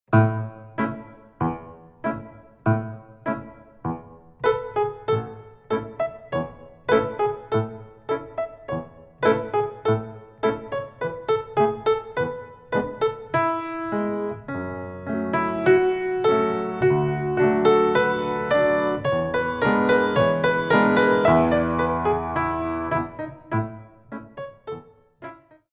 Piano Improvisations